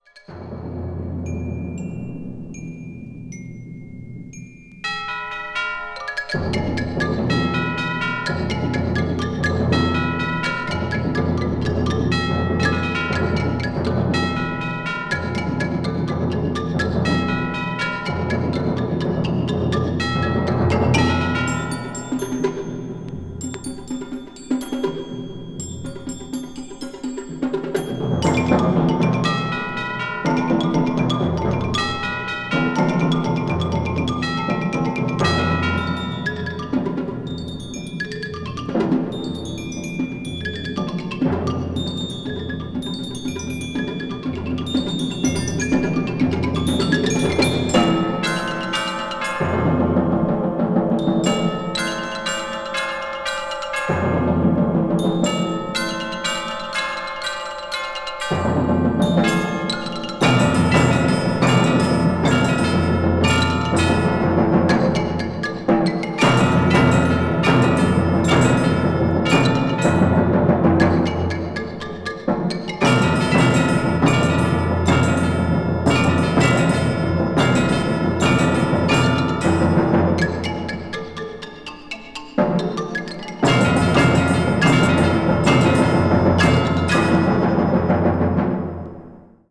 media : EX/EX(わずかにチリノイズが入る箇所あり)
音圧あります。